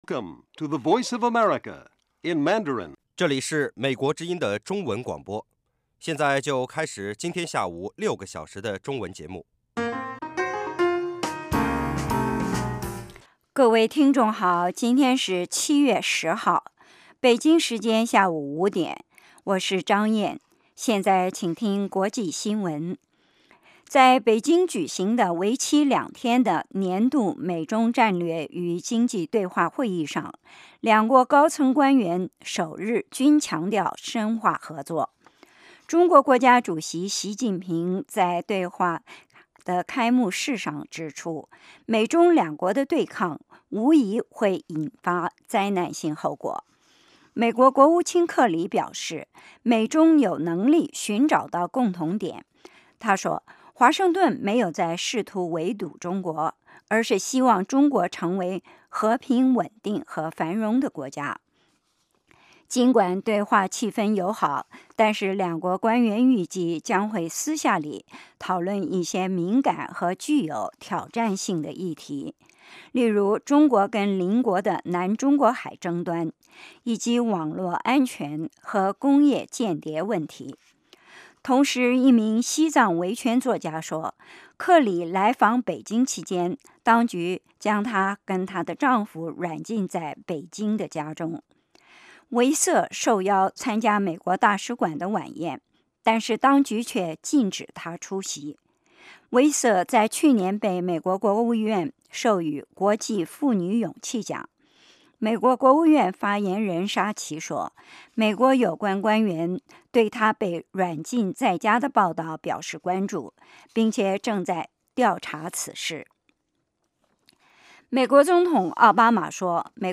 国际新闻 英语教学 社论 北京时间: 下午5点 格林威治标准时间: 0900 节目长度 : 60 收听: mp3